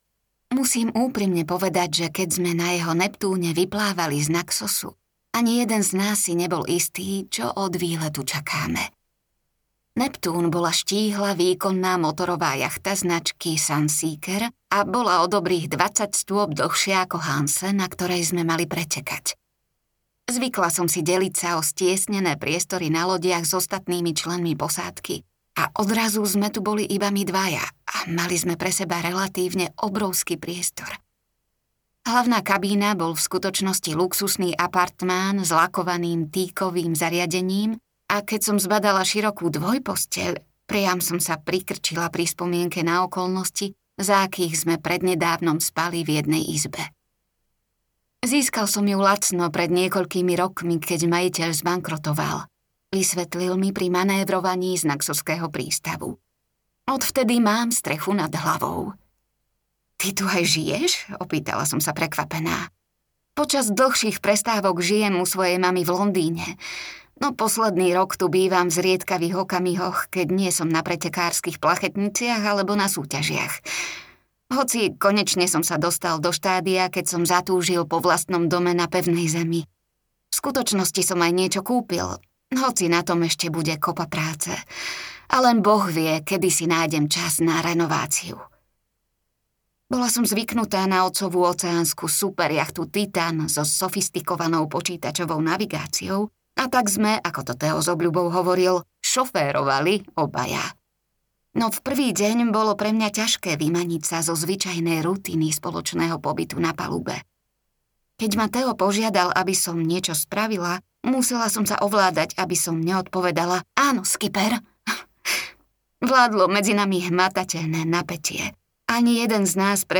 Búrková sestra audiokniha
Ukázka z knihy